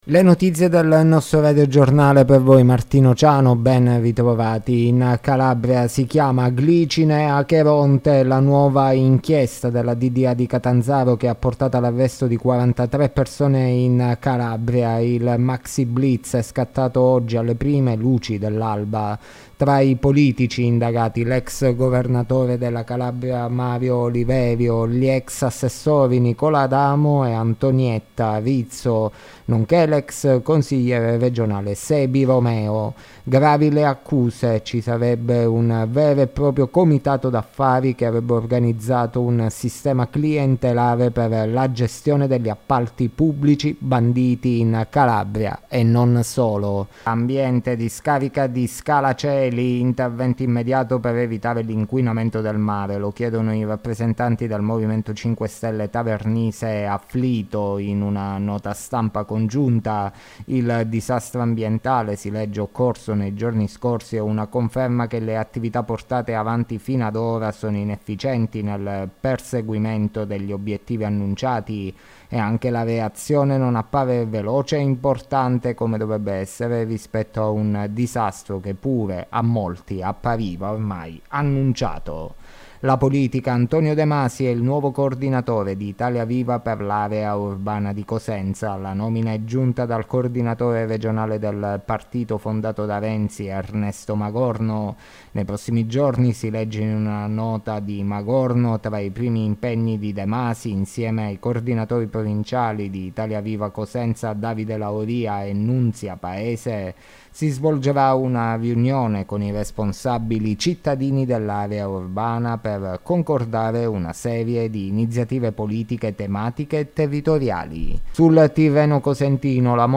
Le notizie della sera Martedì 27 Giugno 2023